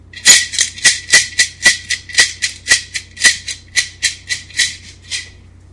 现场录音 " 银行的旋转门
描述：进入银行使用自动取款机，通过安全检查。使用带有低切滤波器的Zoom H1记录仪进行记录
Tag: 银行 现场记录 ATM 噪声 巴西